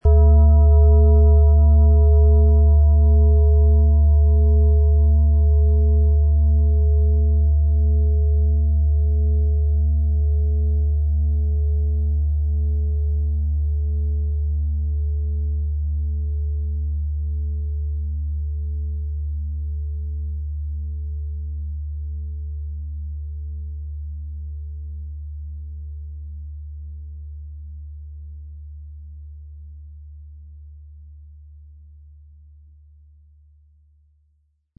So entsteht ein lebendiger, charakterstarker Klang.
• Tiefster Ton: Mars
• Höchster Ton: Neptun